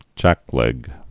(jăklĕg) Chiefly Southern & South Midland US